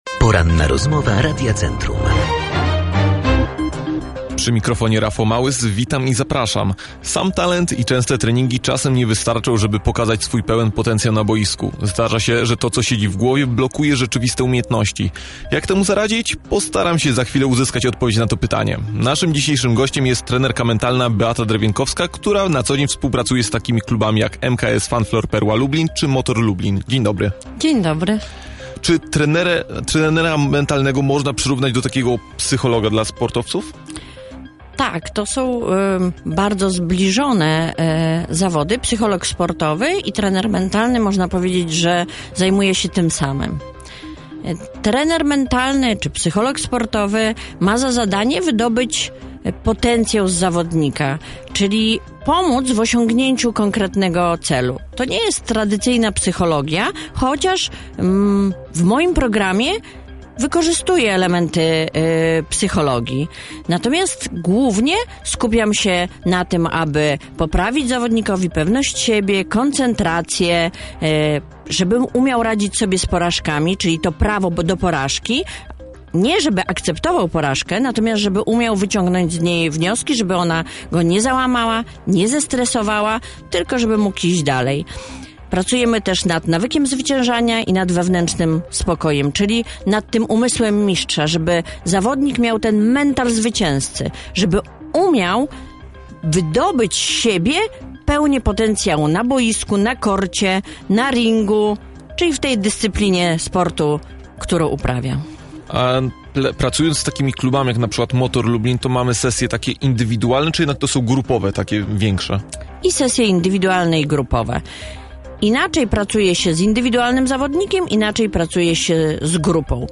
Rozmowa-po-edycji-3.mp3